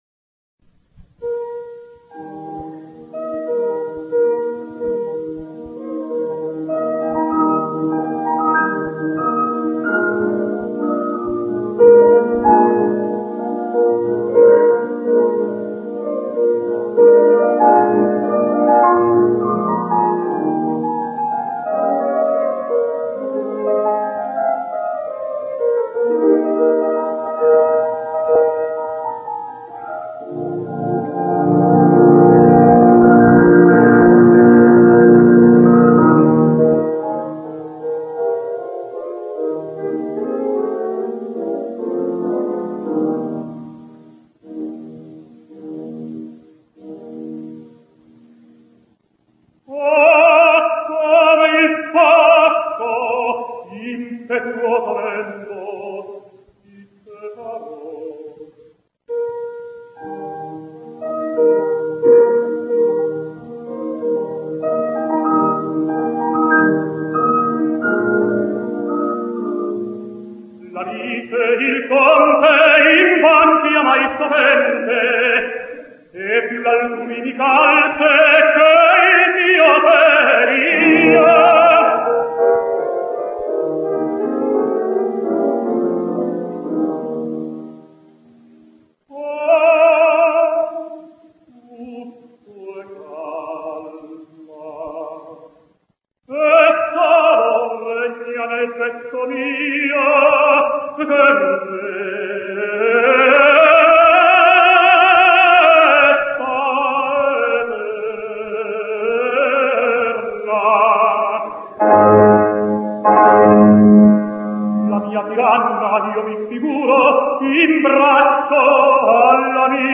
José Carreras sings La pietra del paragone: